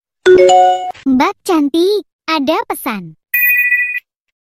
Nada notifikasi Mbak cantik, ada pesan
Kategori: Nada dering
nada-notifikasi-mbak-cantik-ada-pesan-id-www_tiengdong_com.mp3